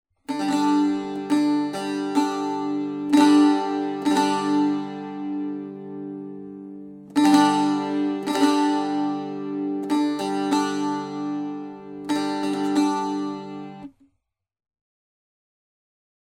At first I used higher tunings, but recently I have settled on a relatively low, warm-sounding tuning of c-g-d.
And this here would be a G5 (no 3rd) -chord:
G-chord (no 3rd)
baglama-saz-g-chord.mp3